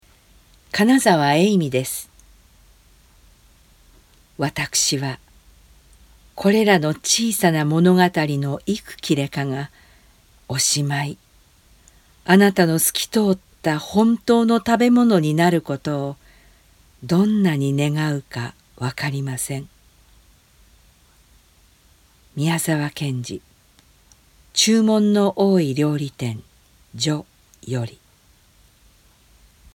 朗読
ボイスサンプル